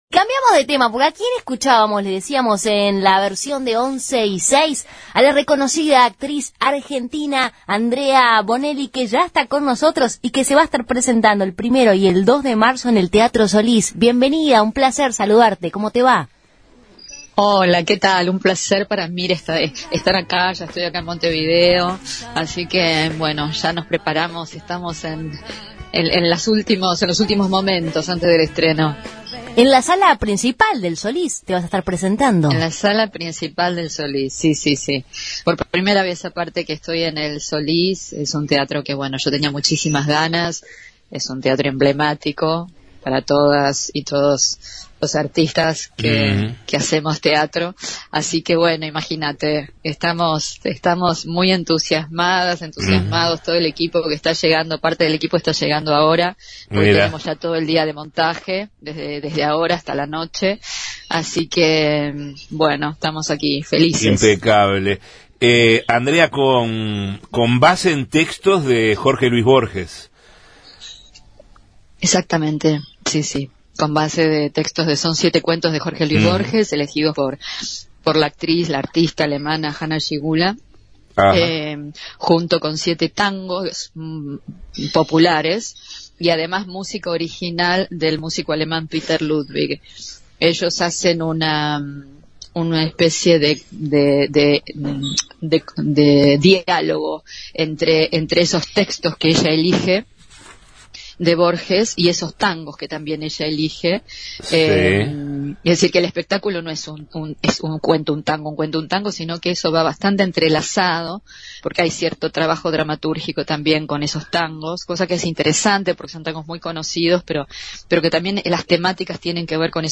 ENTREVISTA-ANDREA-BONELLI.mp3